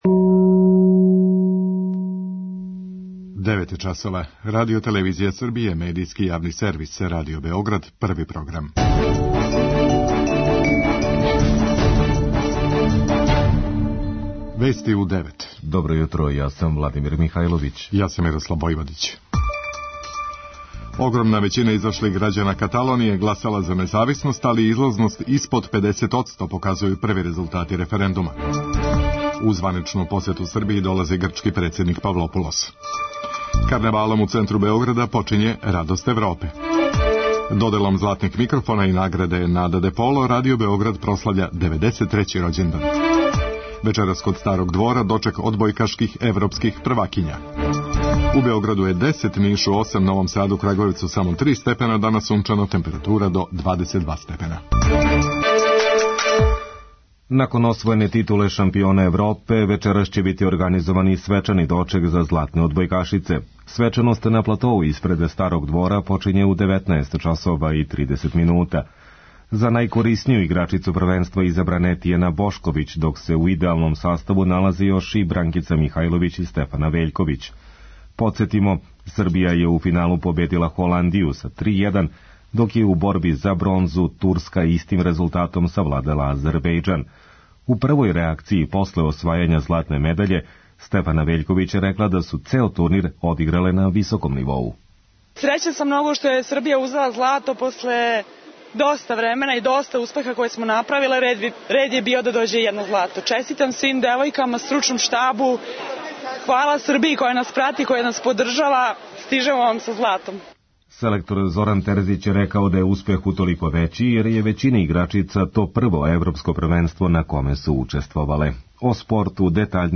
преузми : 3.82 MB Вести у 9 Autor: разни аутори Преглед најважнијиx информација из земље из света.